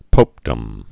(pōpdəm)